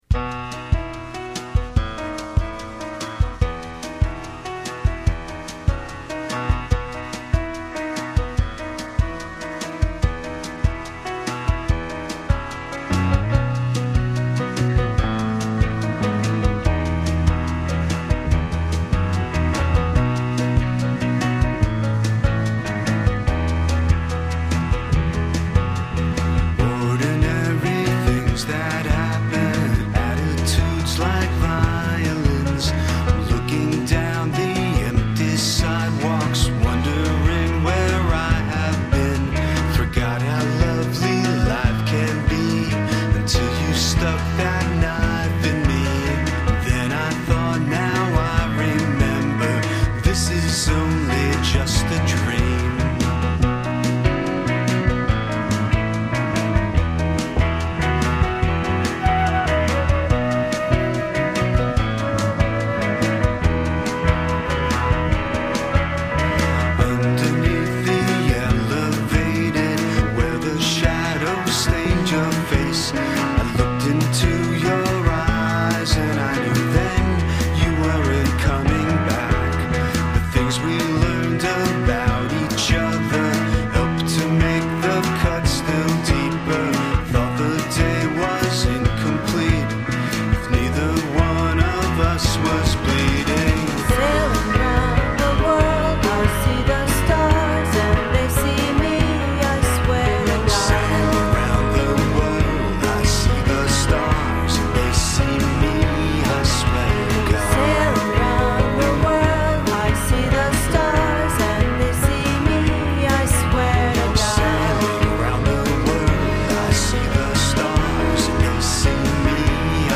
All songs are near-CD quality mp3 (96 kbps - 44 khz).